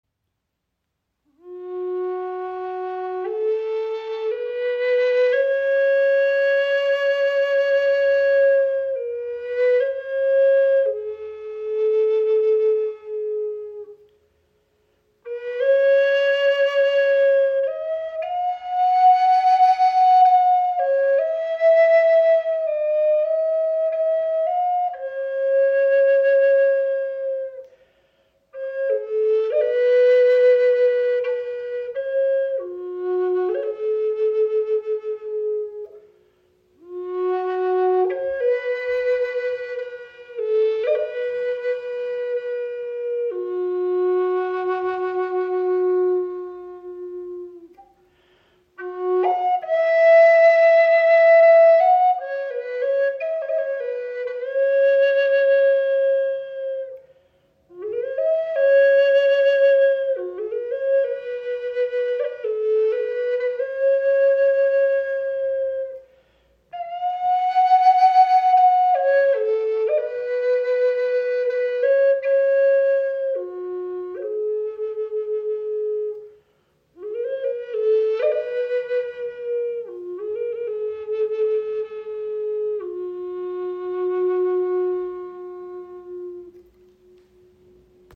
Gebetsflöte High Spirit in F#-Moll | Raben Windblock | mit Türkisen | 57 cm
• Icon Fis-Moll-Stimmung – für ausdrucksstarke, emotionale Klangreisen
• Icon Handgefertigt aus ebonisierter Walnuss – warm, edel, klangstark
Die Crow Flute in Fis-Moll wird aus ebonisiertem Walnussholz handgefertigt und vereint tiefen, klaren Klang mit eleganter Optik.
Die Raben Flute in Fis-Moll ist ein ausdrucksstarkes Instrument mit einer warmen und klaren Klangfarbe.
High Spirits Flöten sind Native American Style Flutes.